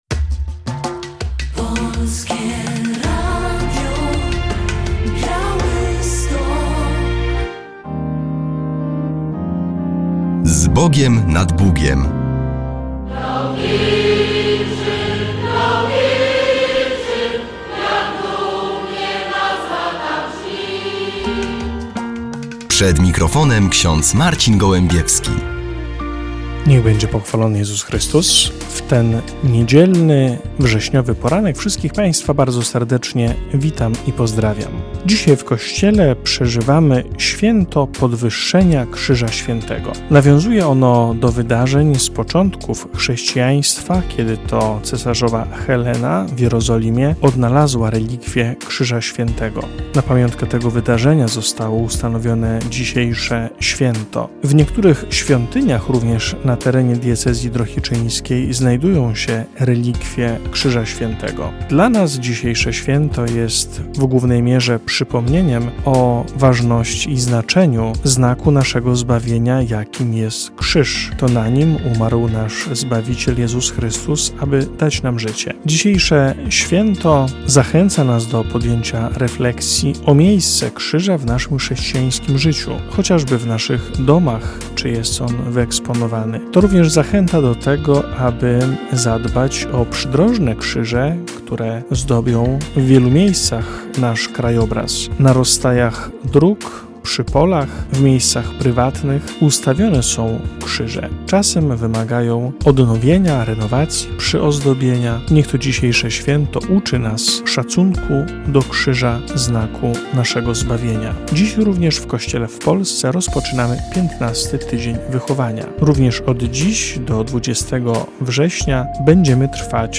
W audycji relacja z jubileuszu 50-lecia Domu Pomocy Społecznej im. Jana Pawła II w Brańsku.